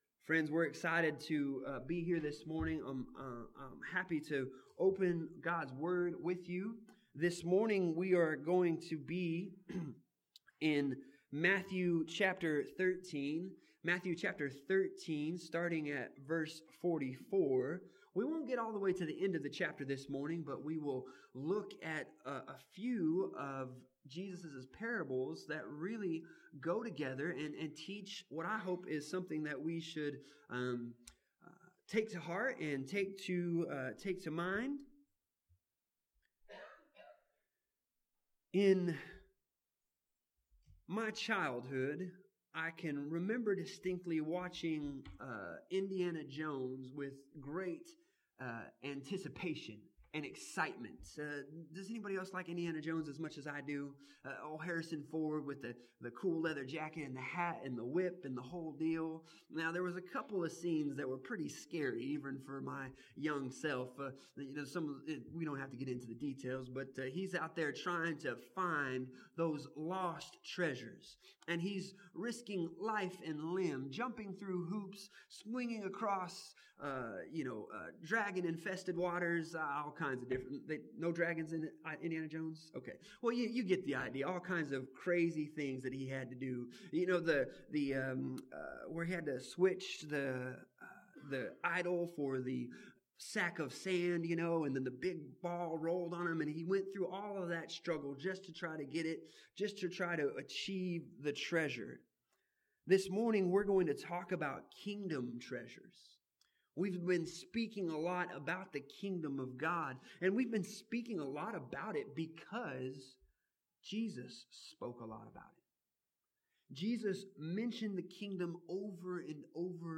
Passage: Matthew 13:44-52 Service Type: Sunday Morning